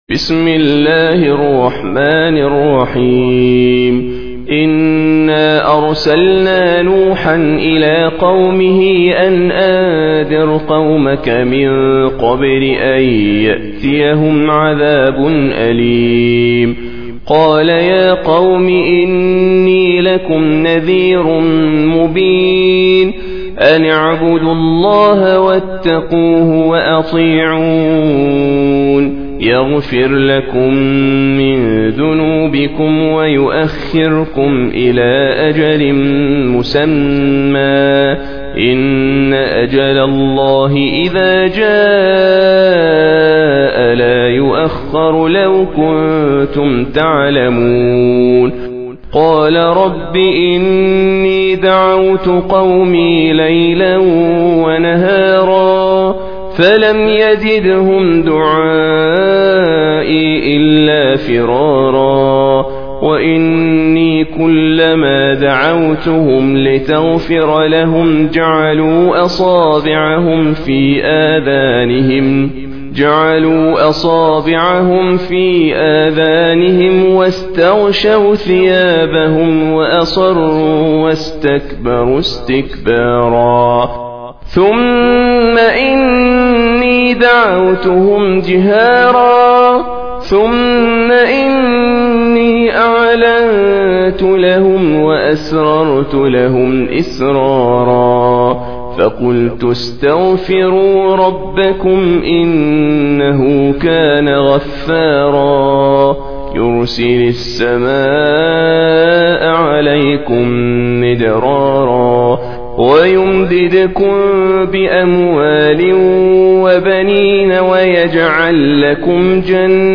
Surah Sequence تتابع السورة Download Surah حمّل السورة Reciting Murattalah Audio for 71. Surah N�h سورة نوح N.B *Surah Includes Al-Basmalah Reciters Sequents تتابع التلاوات Reciters Repeats تكرار التلاوات